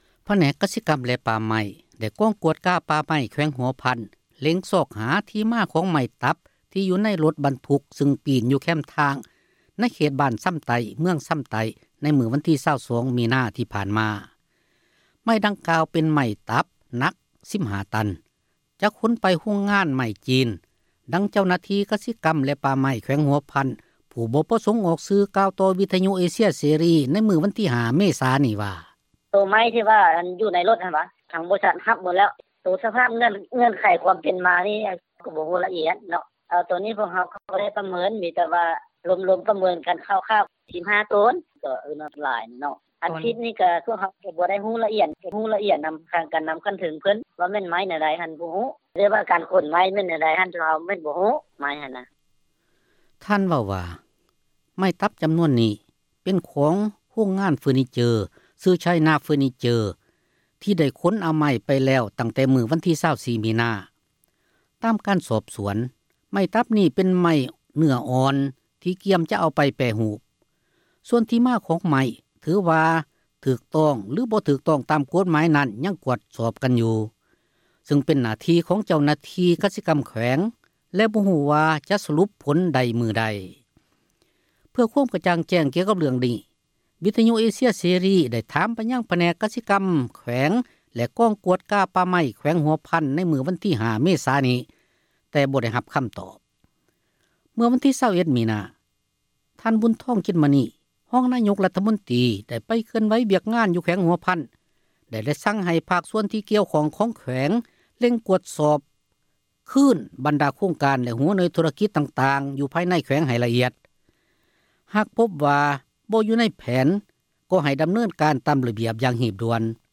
ໄມ້ດັ່ງກ່າວເປັນໄມ້ຕັບໜັກ 15 ຕັນ, ຈະຂົນໄປ ໂຮງງານໄມ້ຂອງຈີນ; ດັ່ງເຈົ້າໜ້າທີ່ ກະສິກັມແລະປ່າໄມ້ ແຂວງຫົວພັນ ຜູ້ບໍ່ປະສົງອອກຊື່ ກ່າວຕໍ່ວິທຍຸເອເຊັຽເສຣີ ໃນມື້ວັນທີ 5 ເມສາ ນີ້ວ່າ: